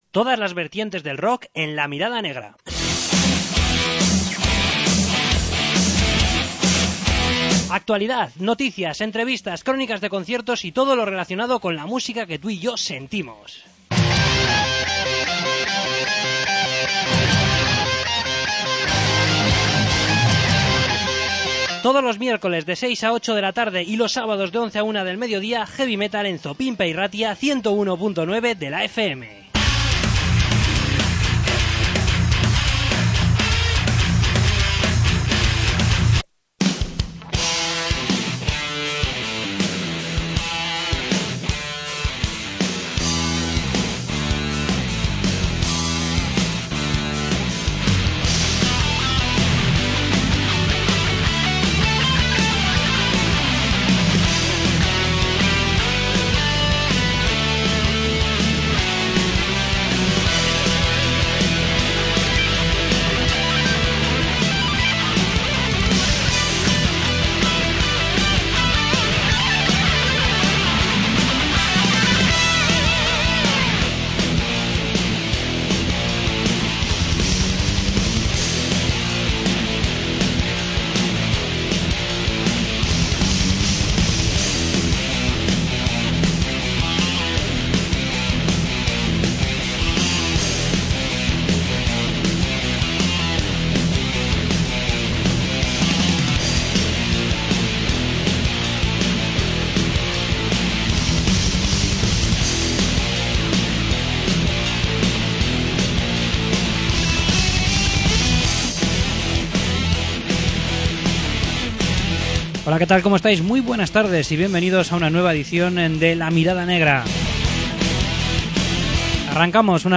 Entrevista con Darksun